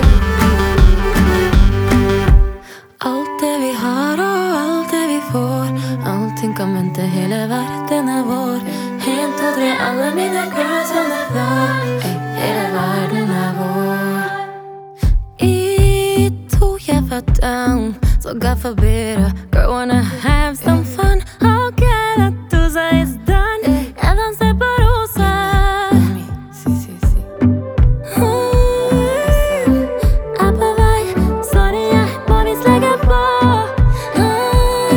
Folk Pop
Жанр: Поп музыка / Фолк